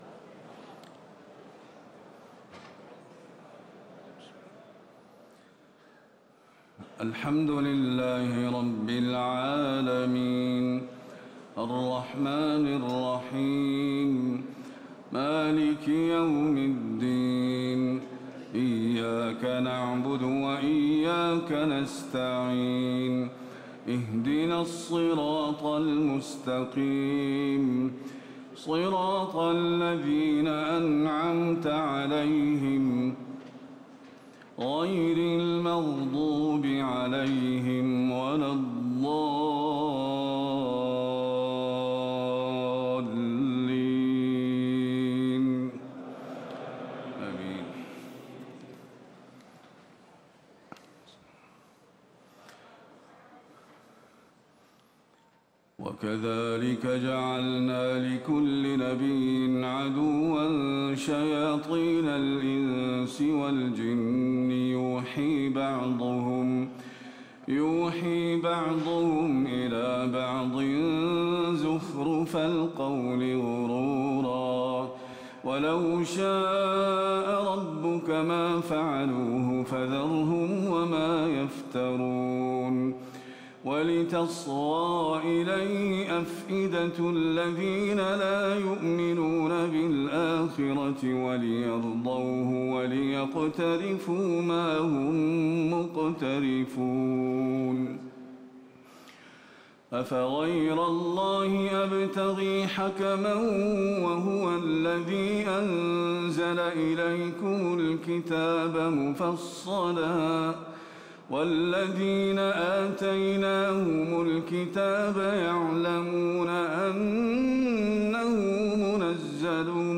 تهجد ليلة 28 رمضان 1438هـ من سورة الأنعام (112-165) Tahajjud 28 st night Ramadan 1438H from Surah Al-An’aam > تراويح الحرم النبوي عام 1438 🕌 > التراويح - تلاوات الحرمين